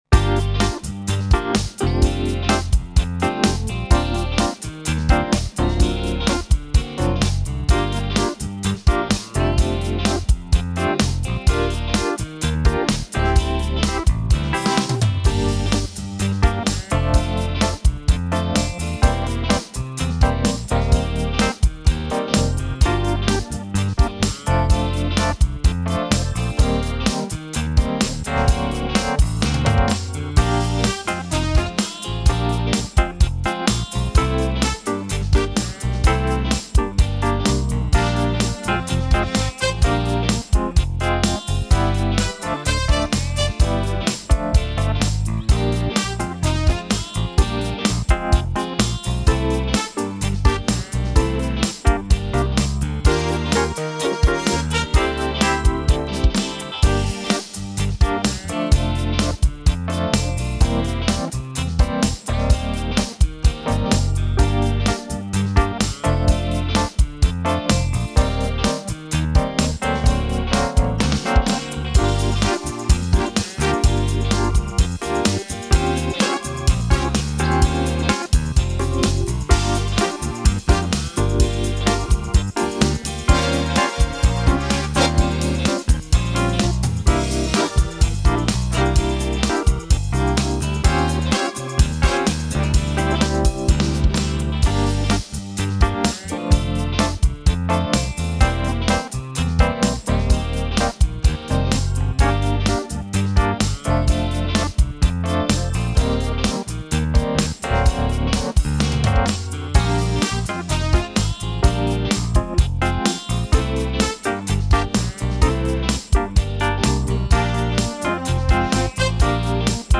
STREET BLUES BackTrack
STREET-BLUES-BackTrack.mp3